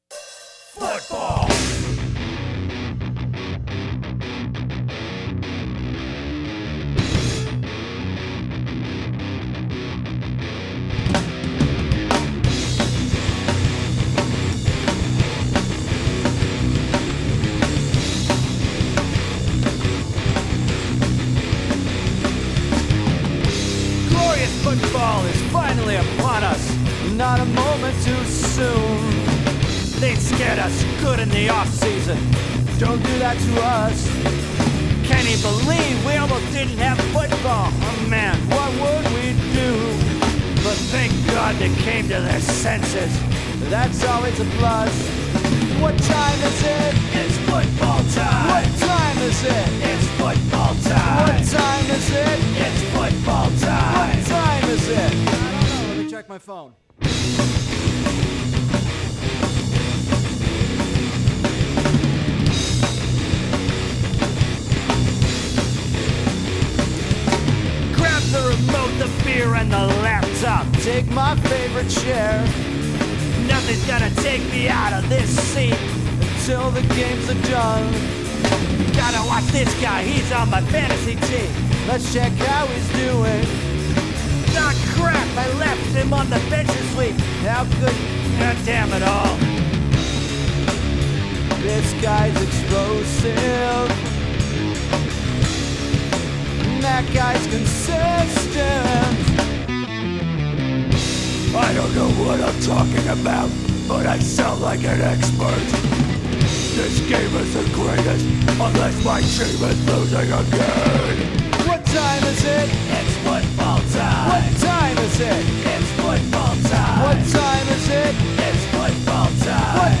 The premise is quite simple: it’s a celebration of all things football in heavy metal form (even leaving your fantasy team’s best player on the bench).